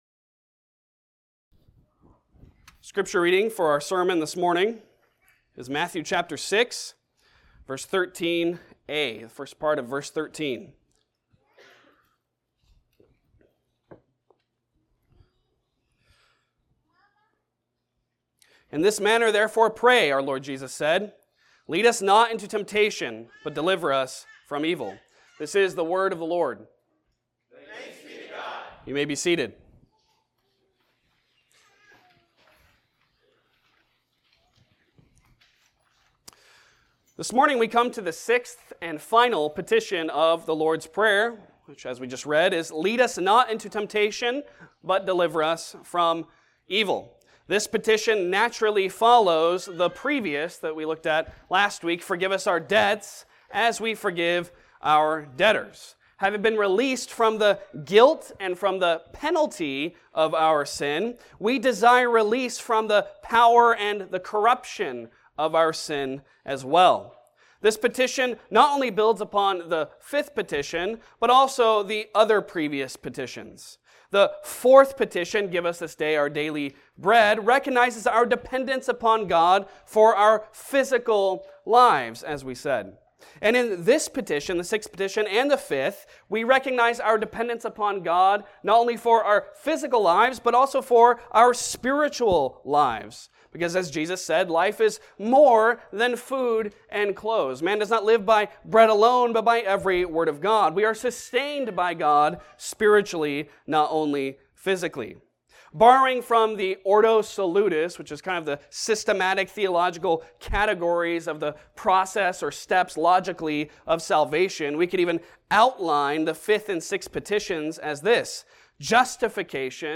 Passage: Matthew 6:13a Service Type: Sunday Sermon